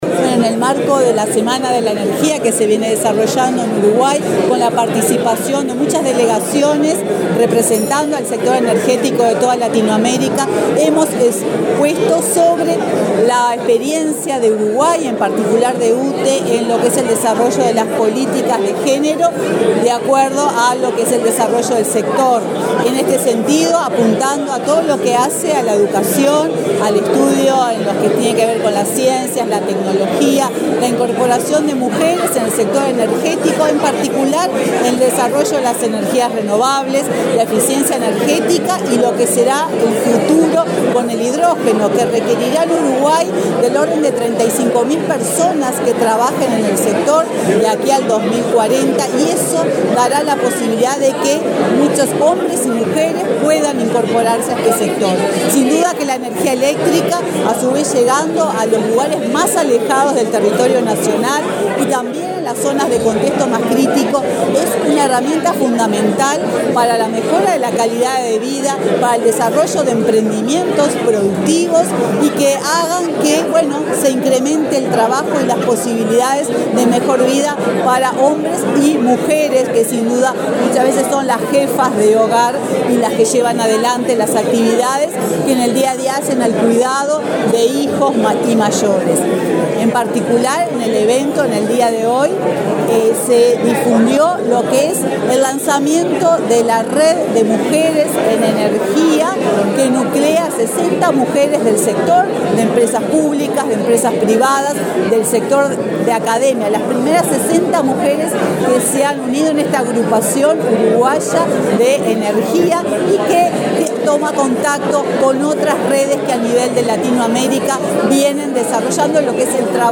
Declaraciones de la presidenta de UTE, Silvia Emaldi
La presidenta de UTE, Silvia Emaldi, expuso, este martes 7 en Montevideo, en el panel sobre evaluación y resultados de políticas y estrategias de equidad de género en el sector, en el marco del Primer Foro de Inversión en Transición Energética para América Latina de la Agencia Internacional de Energías Renovables (Irena). Luego, dialogó con la prensa.